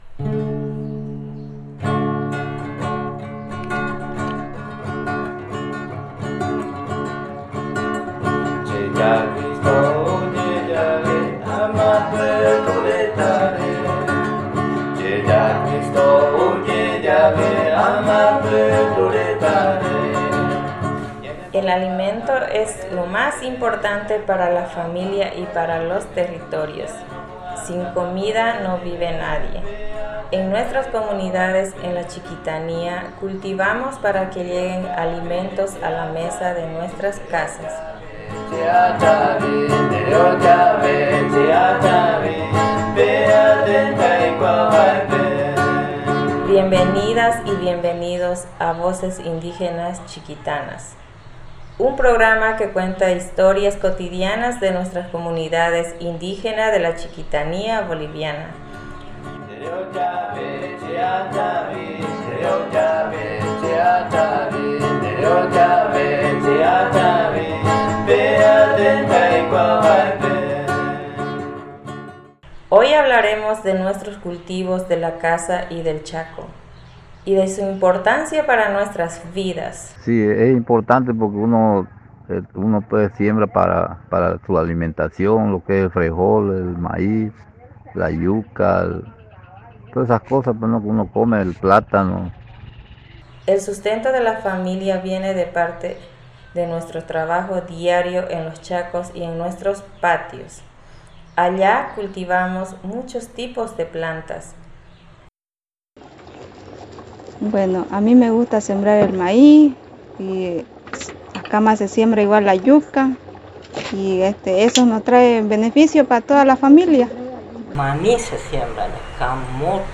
Un capítulo sobre la alimentación en dos comunidades de la Chiquitanía, sus cultivos de la casa y del Chaco y su importancia para la vida de las comunidades. Gracias al trabajo de las y los reporteros de las comunidades San Juan de Lomerío y 16 de Marzo cordillera, en conjunto con el programa Voces Indígenas Urbanas (VIU) y el proyecto Diversidad Biocultural en Paisajes Agrícolas del Sur Global.
La música: Hermanos Hilario – Comunidad 16 de Marzo Cordillera.